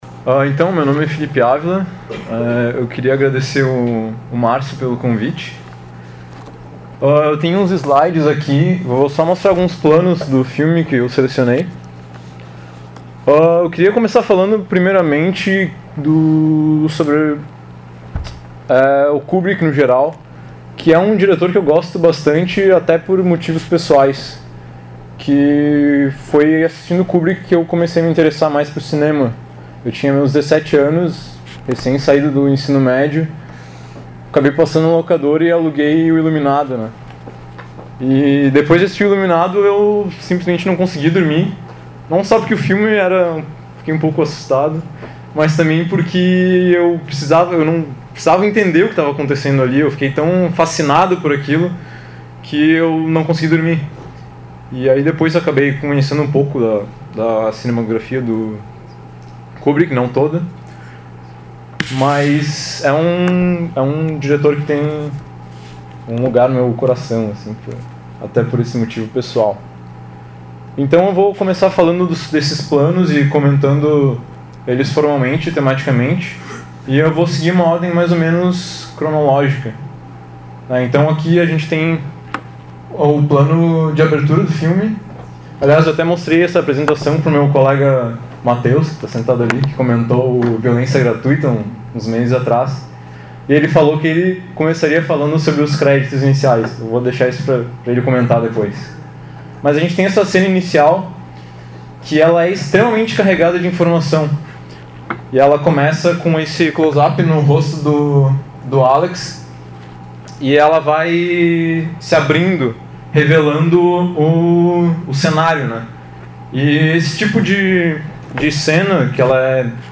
realizada em 30 de junho de 2016 no Auditório "Elke Hering" da Biblioteca Central da UFSC